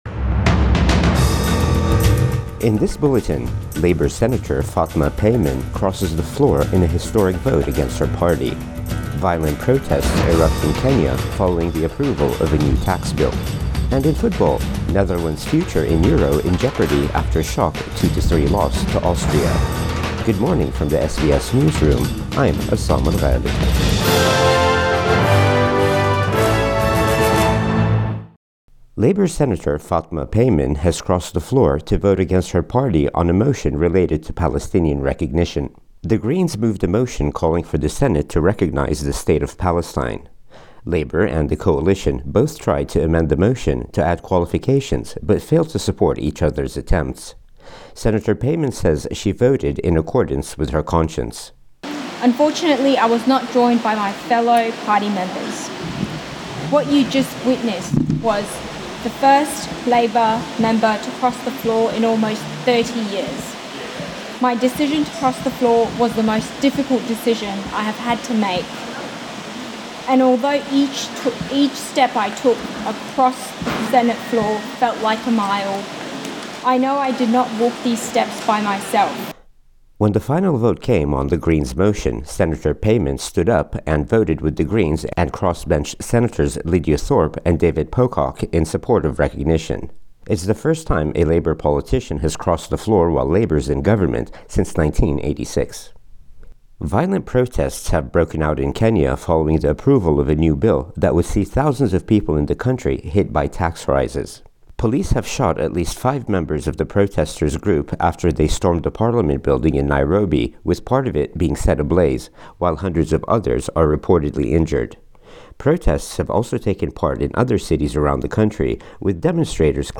Morning News Bulletin 26 June 2024